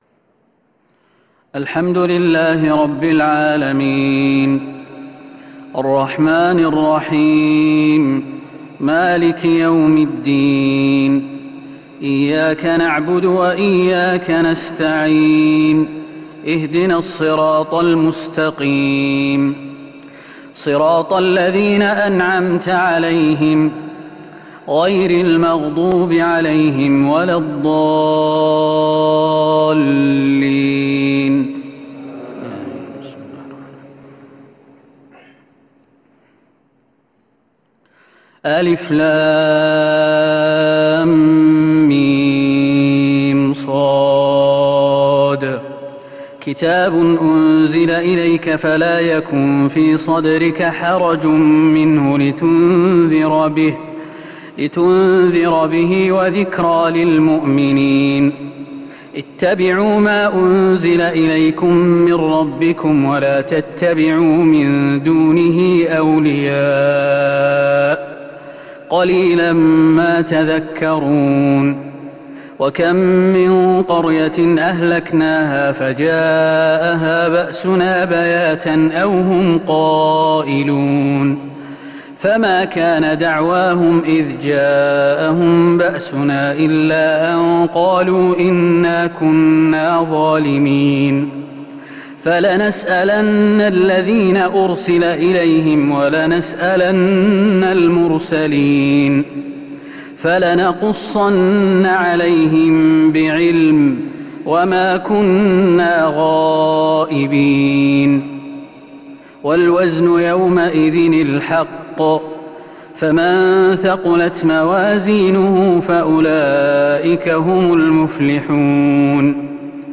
تراويح ليلة 11 رمضان 1442هـ فواتح سورة الأعراف (1-72) |taraweeh 11st niqht ramadan Surah Al-A’raf 1442H > تراويح الحرم النبوي عام 1442 🕌 > التراويح - تلاوات الحرمين